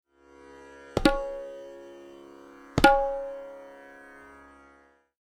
(Bols below: recorded on my tabla)
• Kran/Kra: A combination of ‘Ka + Ra’, played as a non-simultaneous ‘flam’ (i.e. Ka is struck very slightly before Ra), allowing for a subtle disbalancing of the stroke’s timing [n.b. other ‘flam bols’ also exist, including ‘TreKre’: a ‘rolling’ motion onto the syahi with fingers spaced out, interspersed with Ka].
TALAS-Bol-Syllable-Kran.mp3